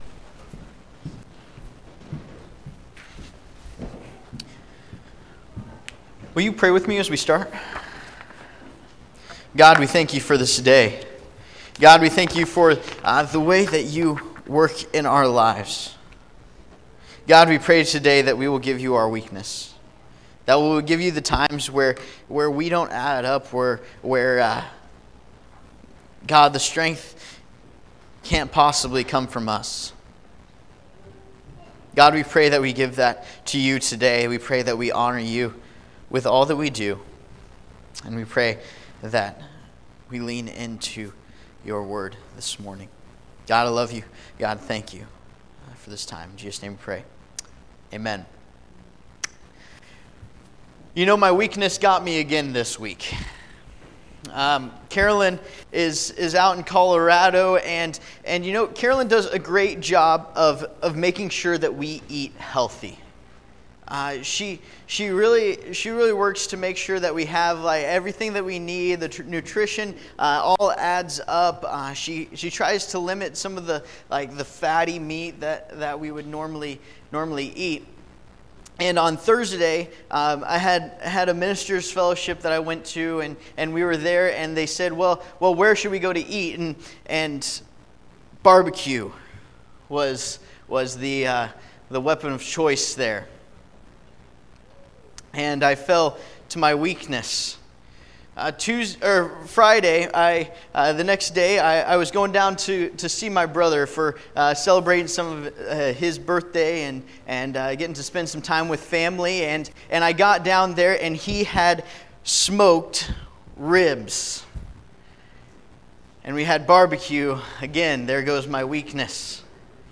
Service Type: Sunday Morning Topics: Victory , Weakness